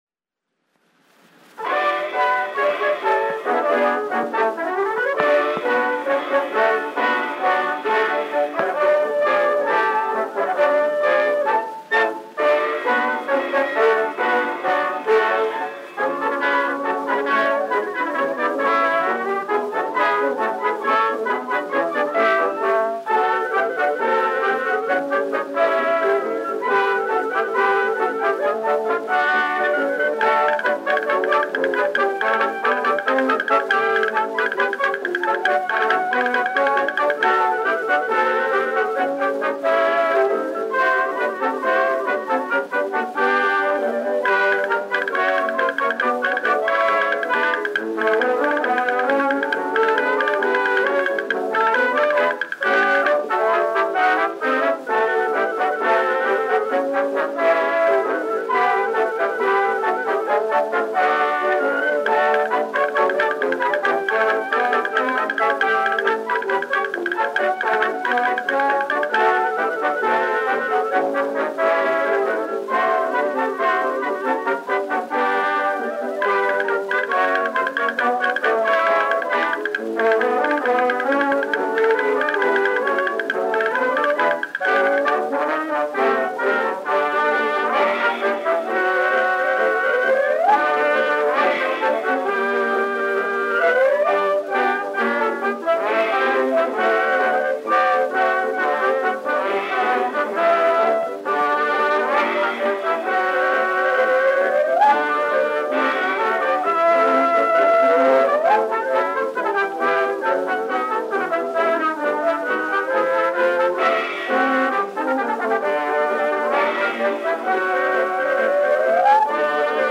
. one-step
Dance music.
Popular instrumental music—1911-1920.
Dance orchestra music.